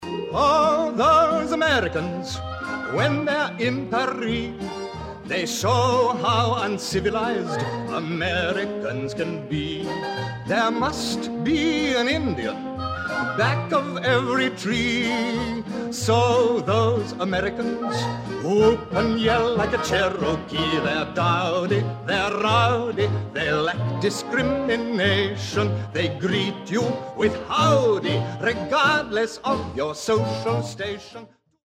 Original Cast